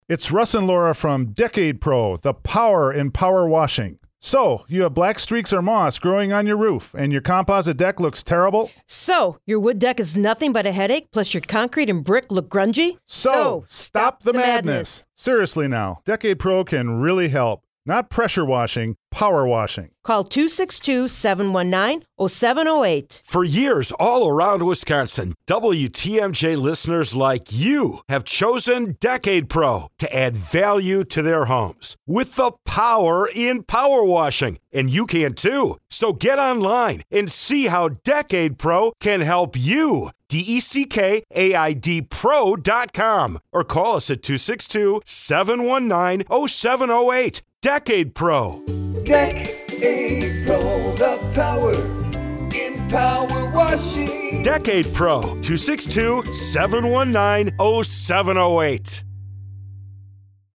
DeckAidPRO Radio Spot on WTMJ
Radio-Spot-4-2016.mp3